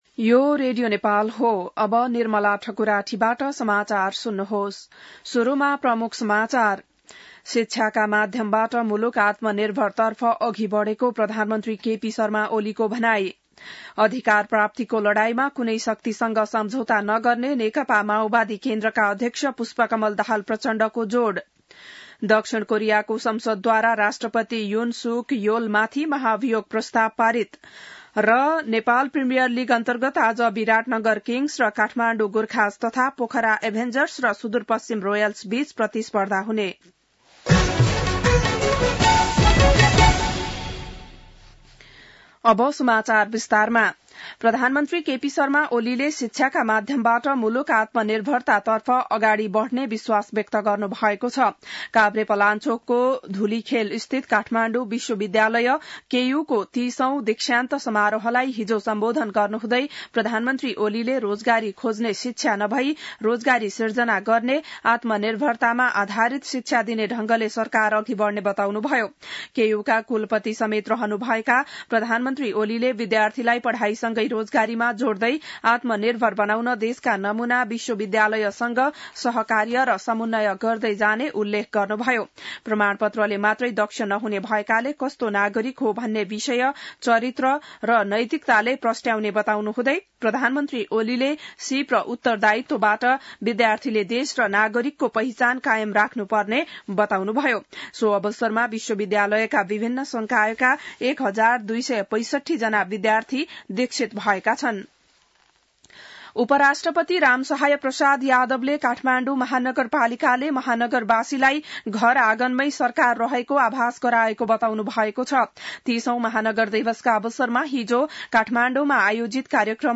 An online outlet of Nepal's national radio broadcaster
बिहान ९ बजेको नेपाली समाचार : १ पुष , २०८१